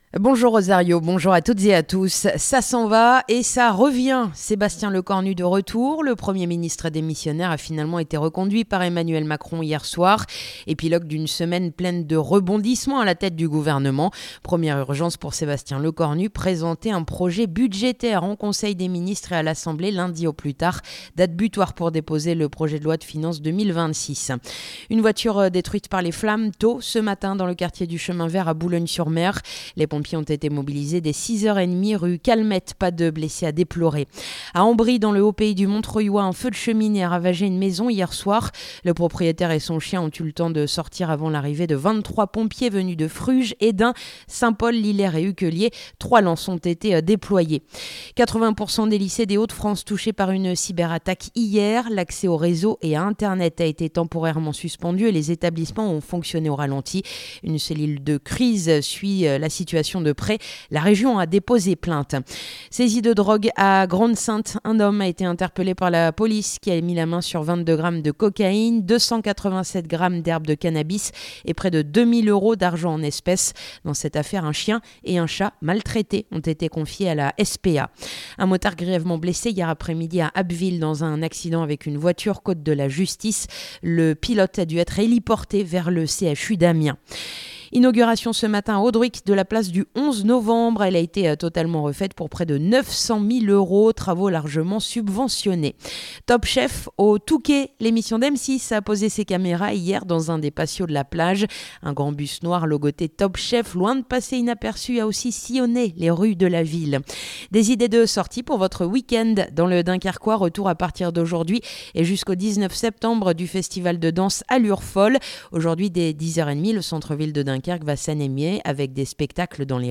Le journal du samedi 11 octobre